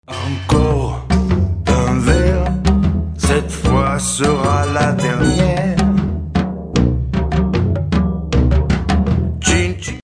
Vocal Version